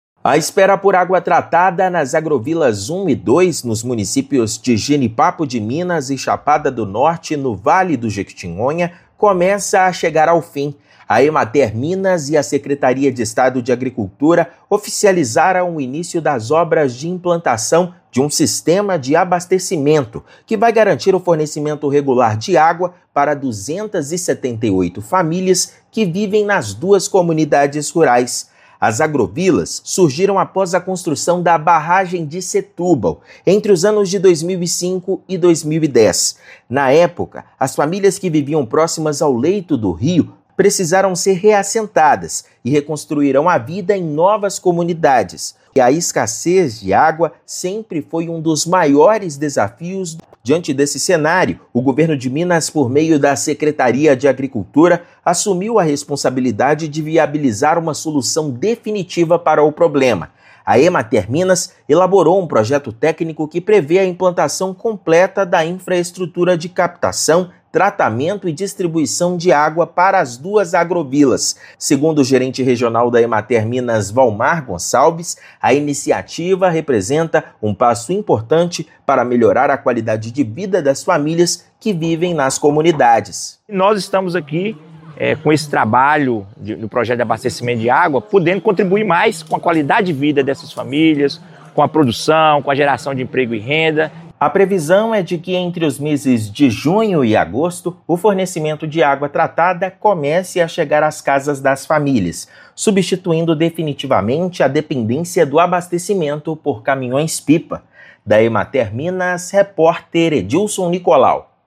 Iniciativa vai beneficiar 278 família de duas agrovilas da região. Ouça matéria de rádio.